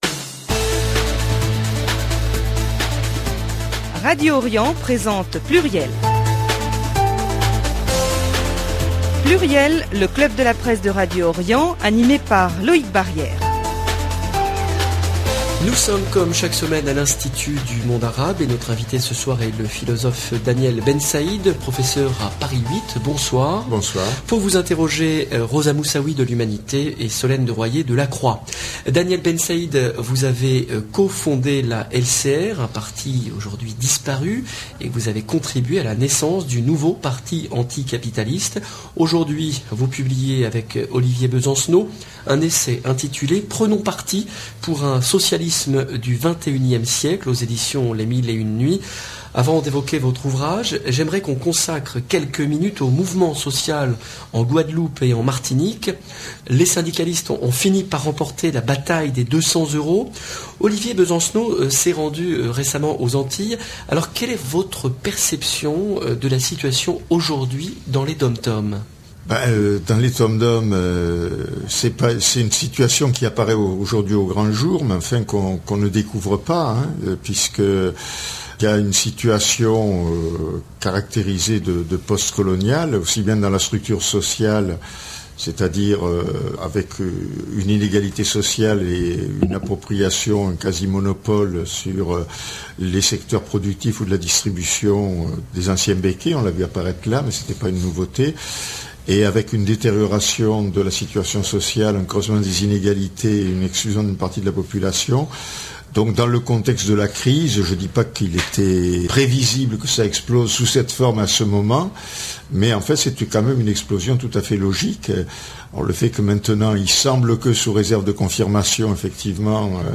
Pluriel a reçu le vendredi 27 février 2009, Daniel Bensaïd, philosophe et professeur des universités à Paris 8.